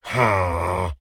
Minecraft Version Minecraft Version 1.21.4 Latest Release | Latest Snapshot 1.21.4 / assets / minecraft / sounds / mob / evocation_illager / idle3.ogg Compare With Compare With Latest Release | Latest Snapshot